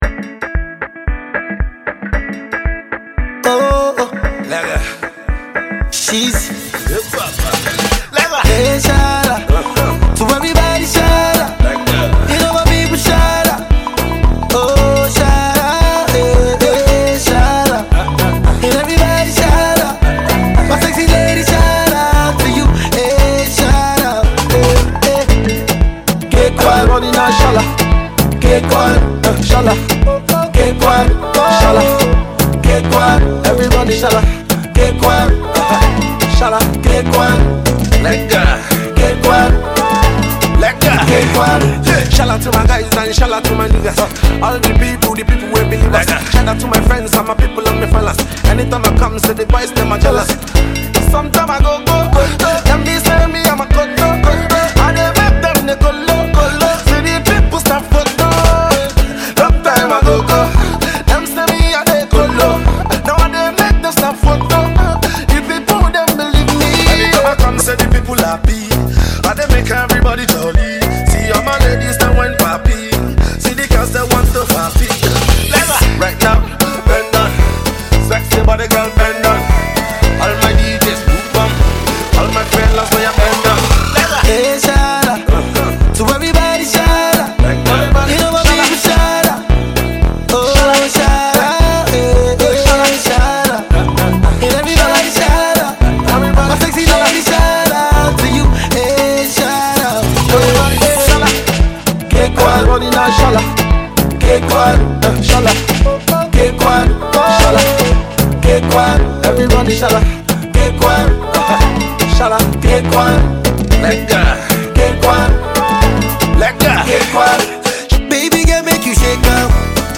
club banger